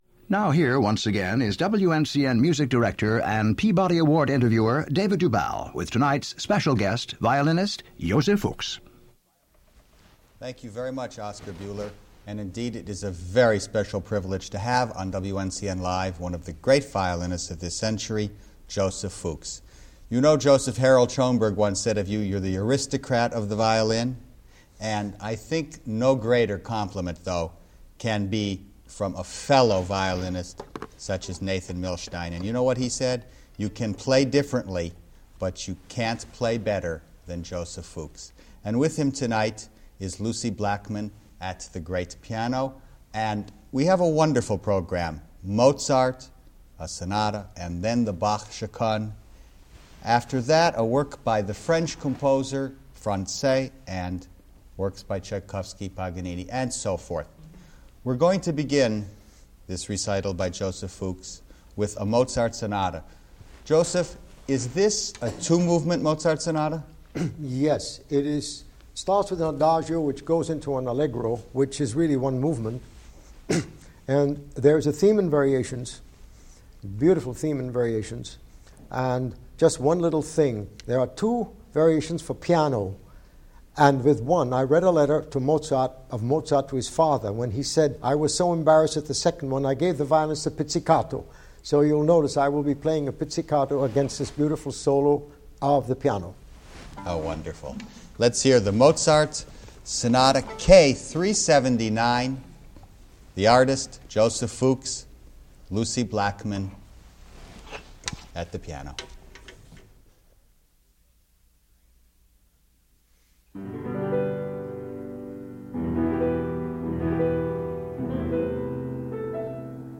Legendary violinist Joseph Fuchs in recital this week. A rare and classic broadcast recital from New York fine arts station WNCN-FM
featuring American violinist Joseph Fuchs
pianist
the informal recital is peppered with reminiscences and anecdotes in the course of the 90 minute program, making for an informative as well as historic recital.